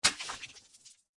water.mp3